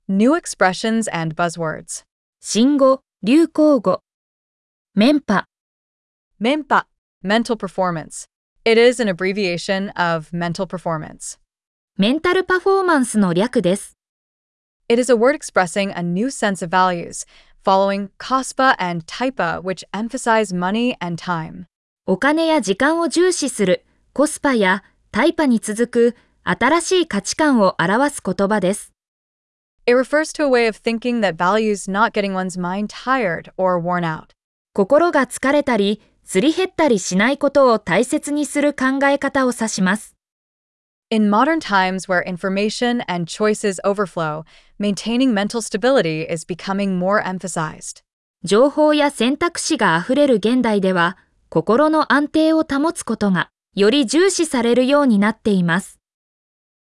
🗣 pronounced: Menpa